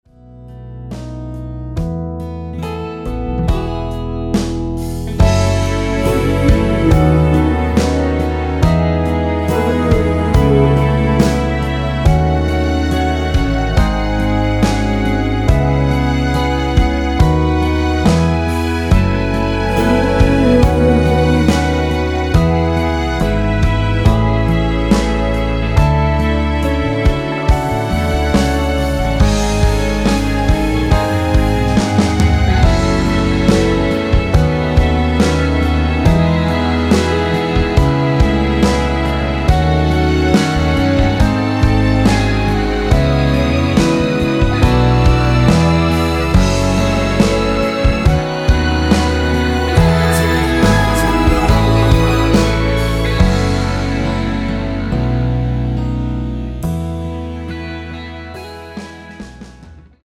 원키에서(+2)올린 코러스 포함된 MR 입니다.(미리듣기 참조)
앞부분30초, 뒷부분30초씩 편집해서 올려 드리고 있습니다.
중간에 음이 끈어지고 다시 나오는 이유는